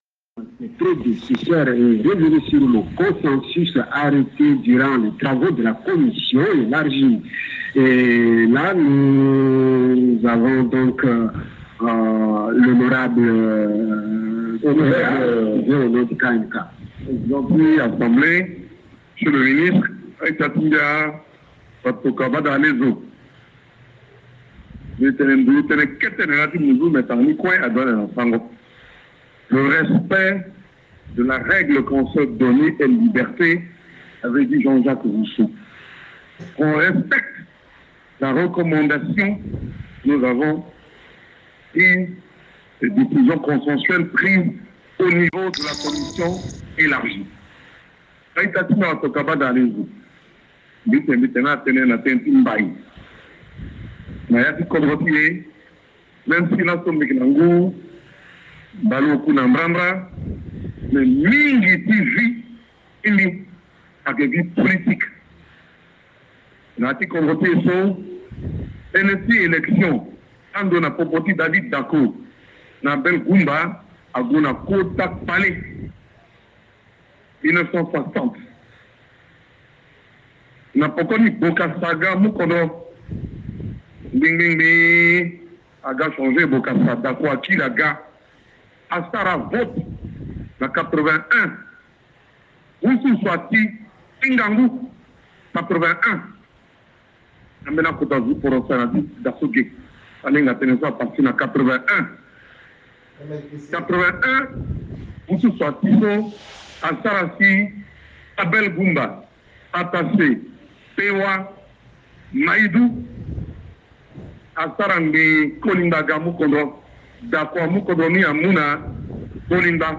Centrafrique : les bandes sonores des débats relatifs au projet de la loi organique de l’ANE (2)